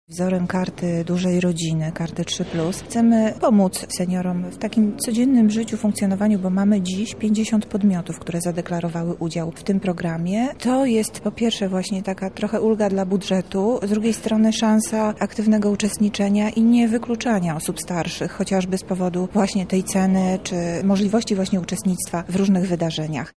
– Chcieliśmy w ten sposób wykonać ukłon w stronę seniorów – wyjaśnia Monika Lipińska, zastępca prezydenta miasta Lublin: